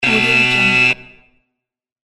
Download Video Game Treasure sound effect for free.
Video Game Treasure